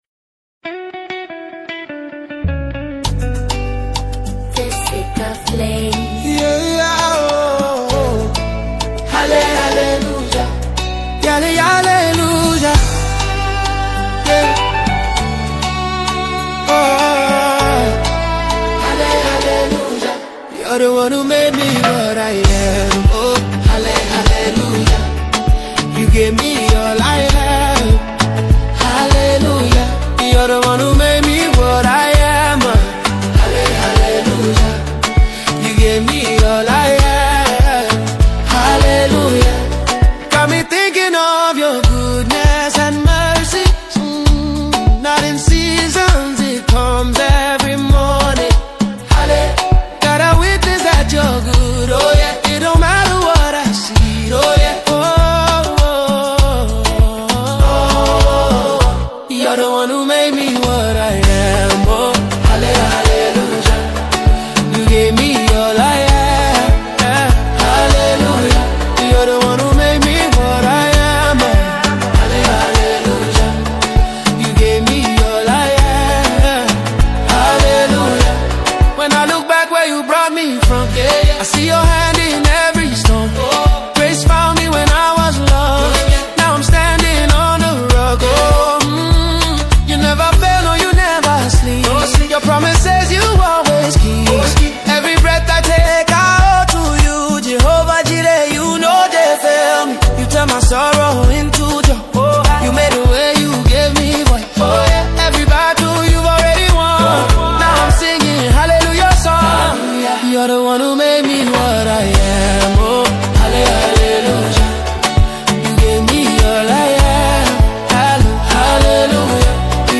feel-good energy and irresistible rhythm